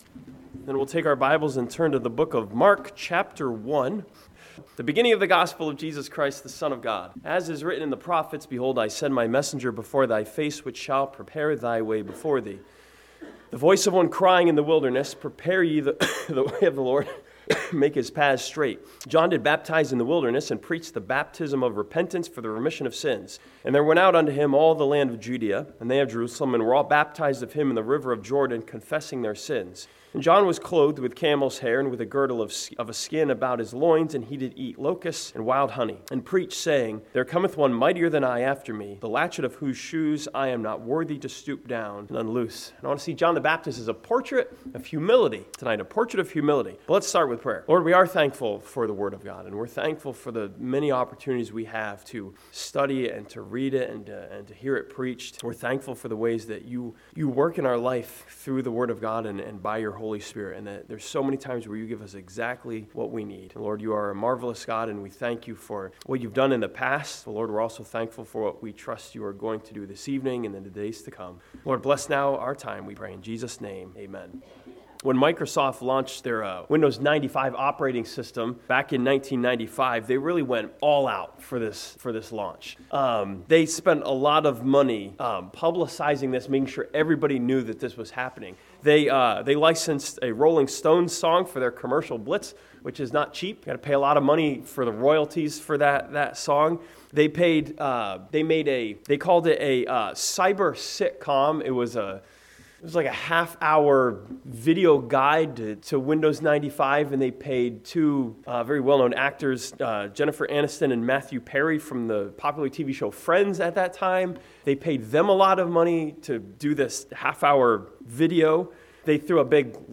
This sermon from Mark chapter 1 looks at John the Baptist as a portrait of humility despite him having every reason to be proud.